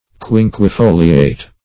Quinquefoliate \Quin`que*fo"li*ate\
quinquefoliate.mp3